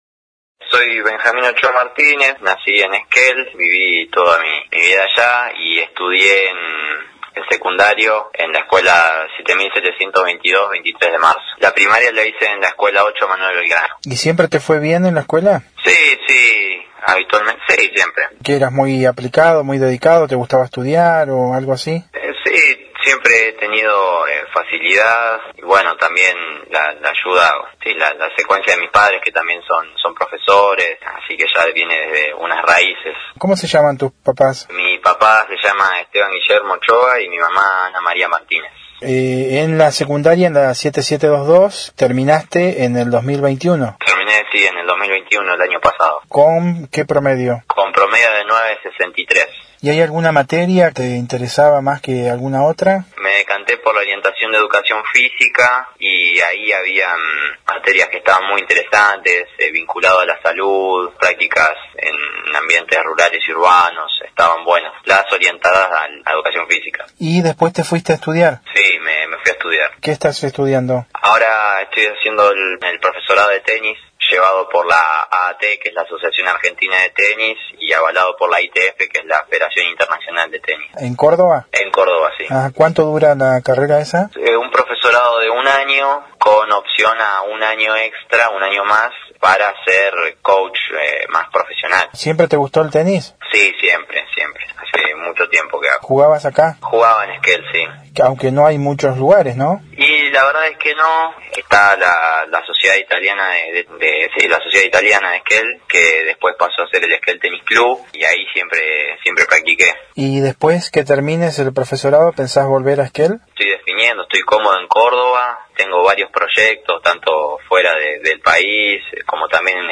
En diálogo con Noticias de Esquel, recordó su etapa escolar y nos contó sus proyectos para el futuro.